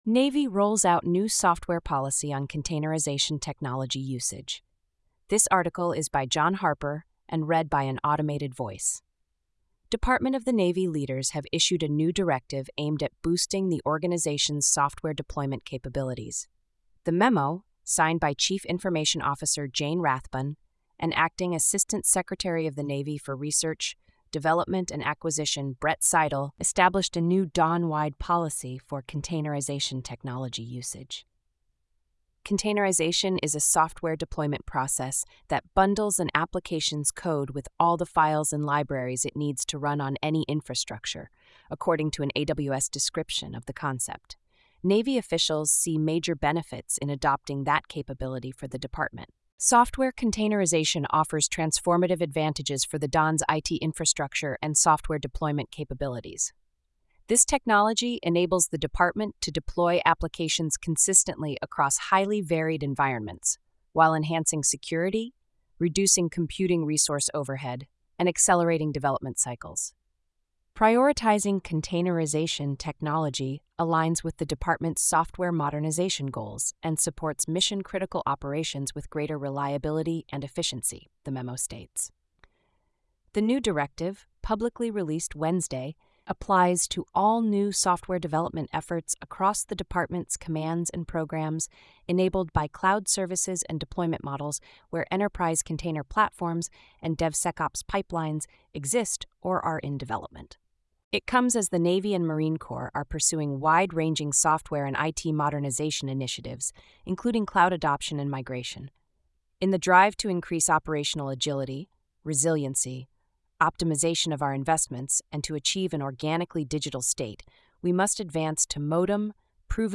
This feature uses an automated voice, which may result in occasional errors in pronunciation, tone, or sentiment.